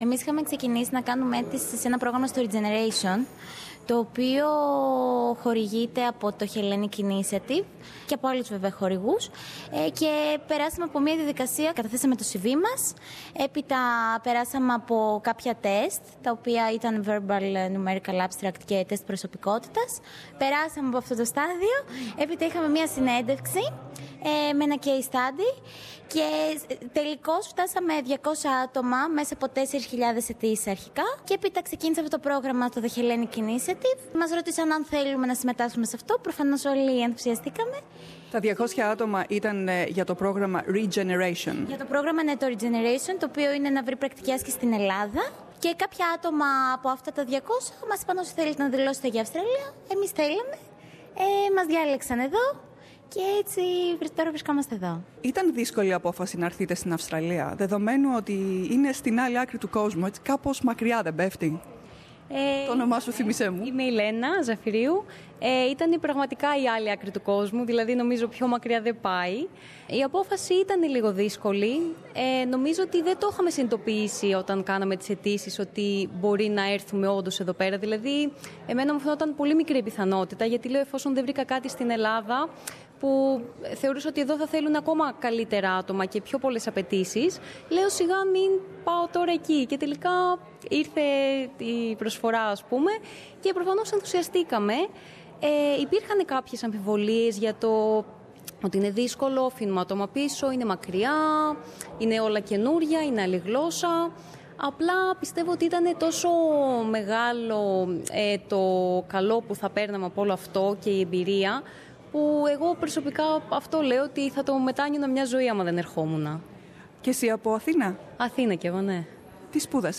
spoke to some of the interns at the event.